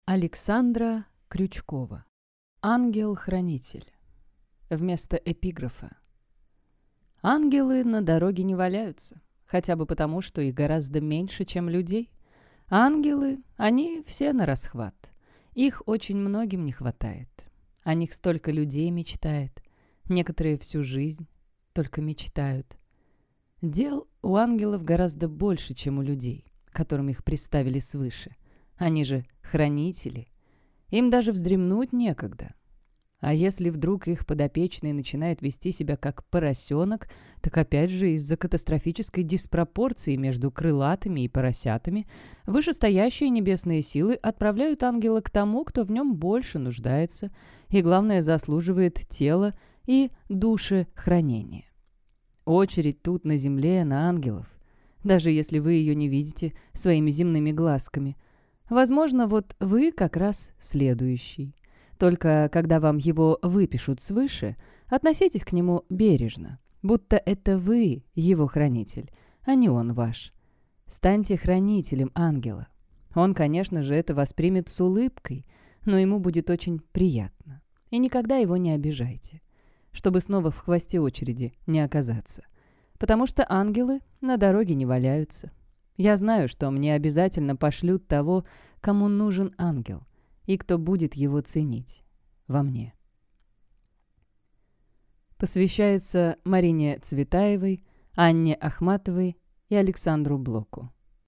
Аудиокнига Ангел-Хранитель. Премия им. Оскара Уайльда. Игра в Иную Реальность | Библиотека аудиокниг